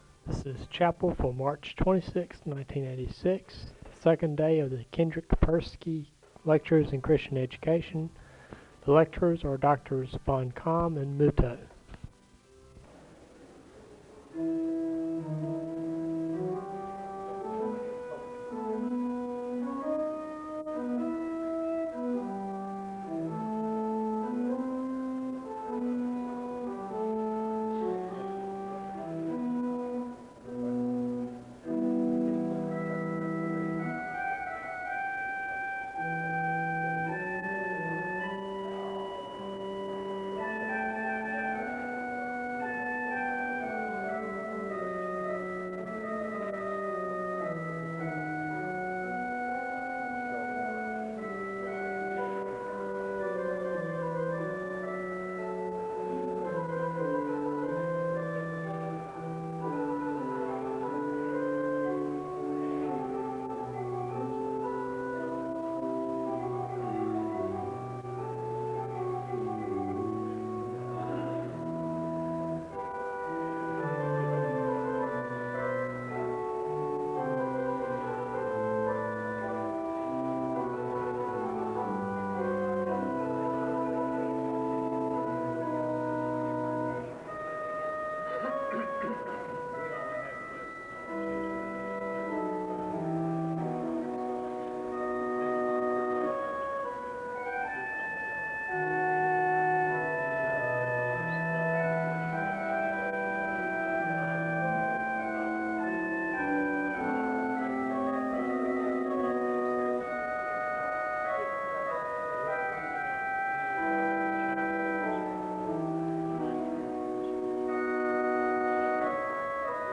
The service begins with organ music (0:00:00-0:02:43). There is a moment of prayer (0:02:44-0:04:30). A welcome is extended to the guests (0:04:31-0:05:33).
The service closes with a benediction (1:02:06-1:02:22).